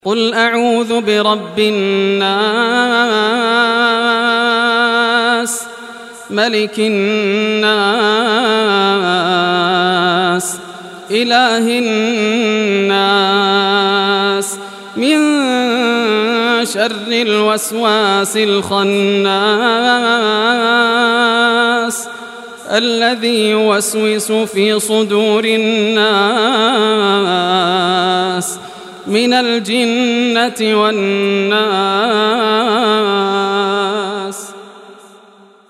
Surah Nas Recitation by Yasser al Dosari
Surah Nas, listen or play online mp3 tilawat / recitation in Arabic in the beautiful voice of Sheikh Yasser al Dosari.
114-surah-nas.mp3